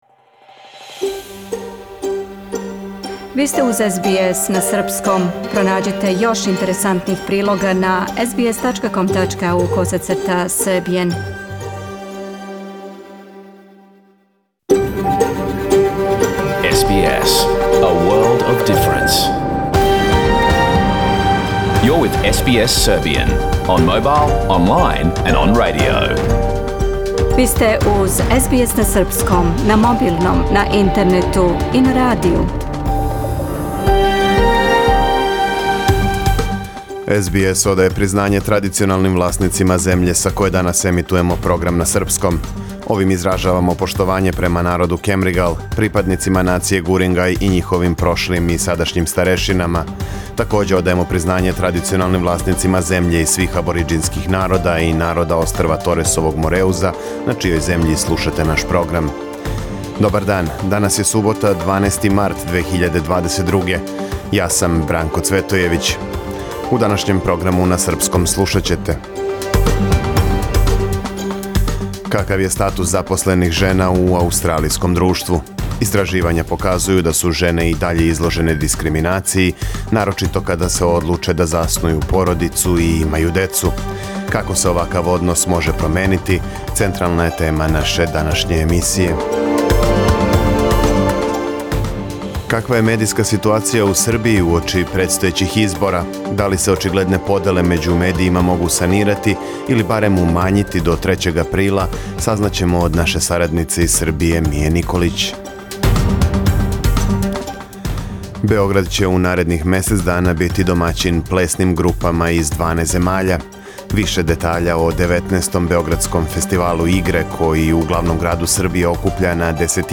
Програм емитован уживо 12. марта 2022. године